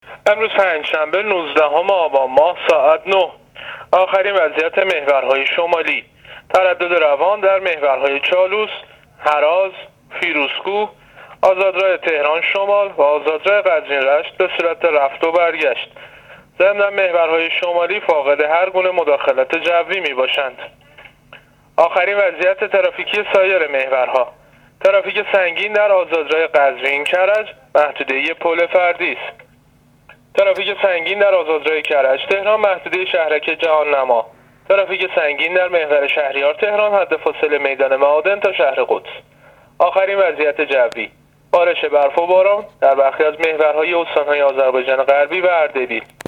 گزارش رادیو اینترنتی از آخرین وضعیت ترافیکی جاده‌ها تا ساعت ۹ نوزدهم آبان؛